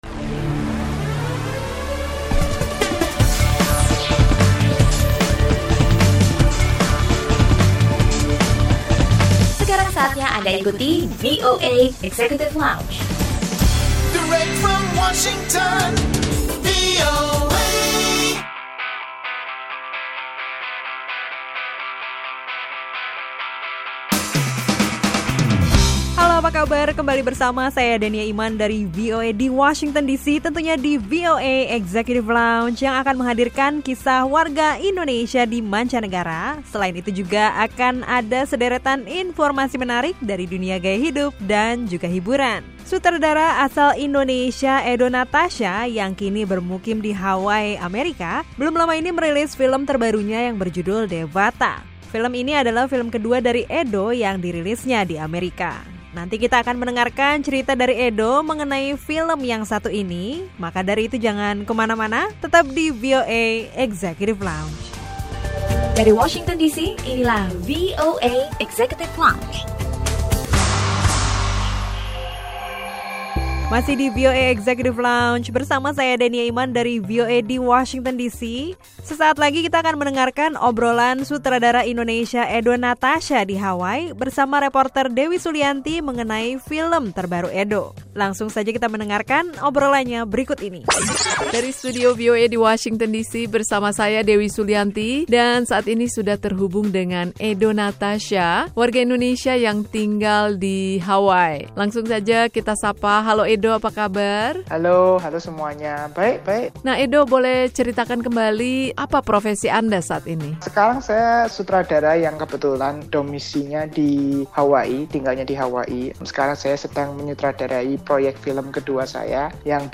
Simak obrolan bersama sutradara Indonesia